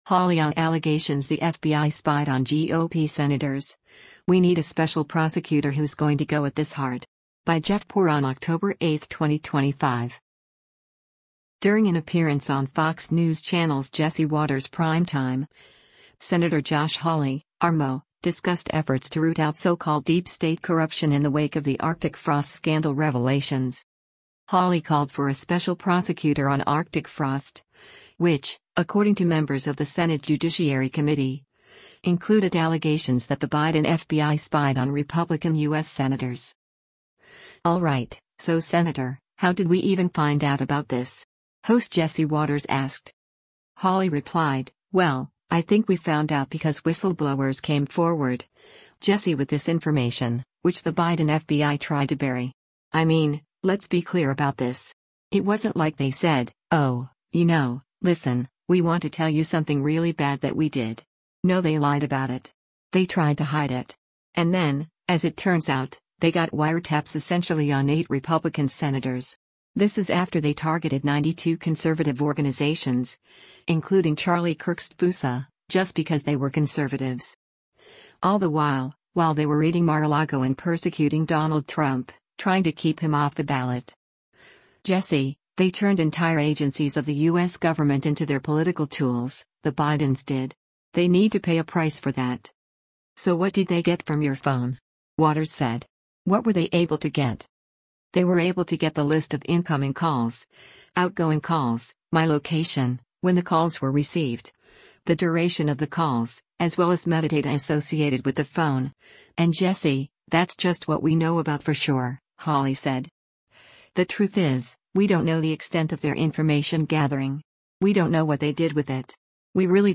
During an appearance on Fox News Channel’s “Jesse Watters Primetime,” Sen. Josh Hawley (R-MO)  discussed efforts to root out so-called deep state corruption in the wake of the “Arctic Frost” scandal revelations.
“All right, so Senator, how did we even find out about this?” host Jesse Watters asked.